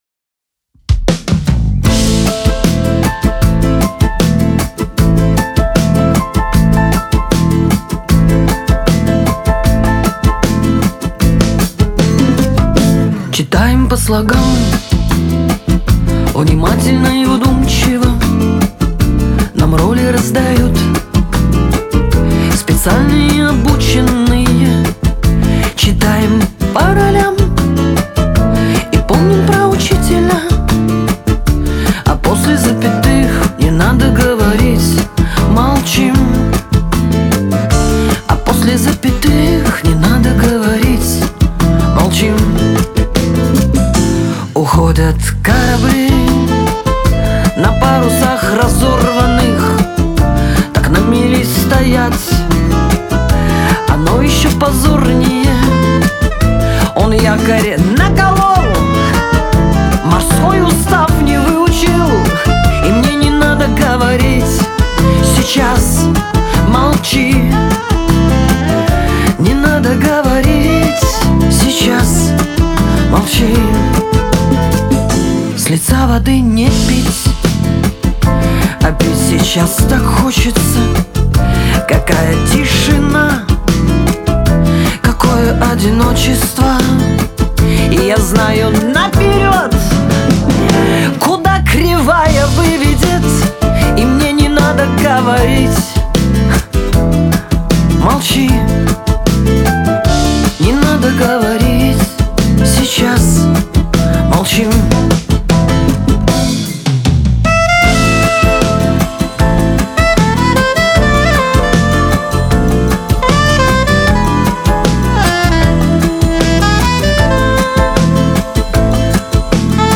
это проникновенная баллада в жанре русской поп-музыки.